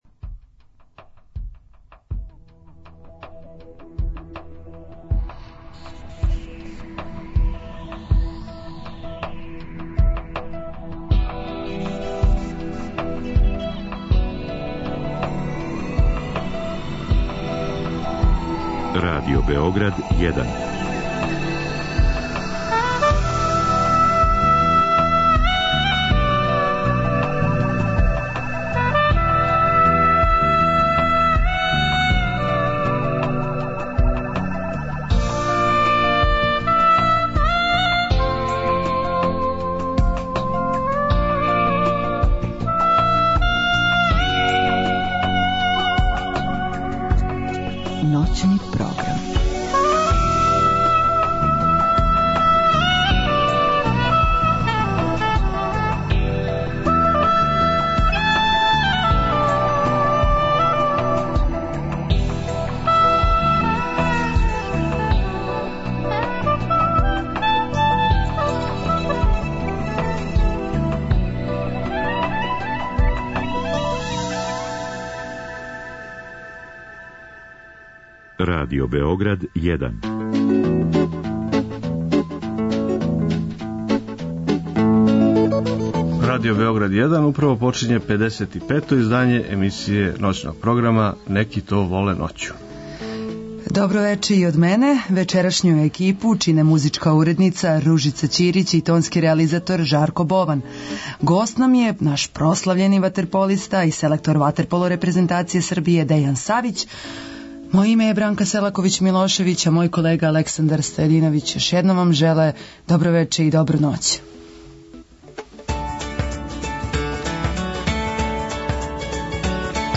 Гост емисије је некадашњи репрезентативац, а сада селектор ватерполо репрезентације Србије, Дејан Савић. Разговараћемо о његовој играчкој и тренерској каријери, о стању у српском ватерполу, о предстојећем Европском првенству...